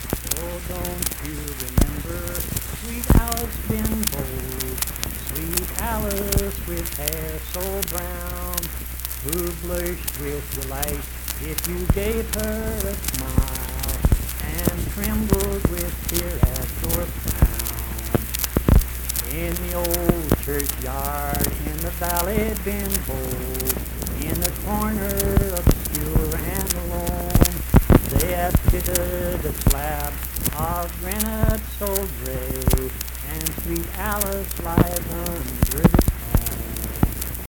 Accompanied (guitar) and unaccompanied vocal music
Performed in Mount Harmony, Marion County, WV.
Voice (sung)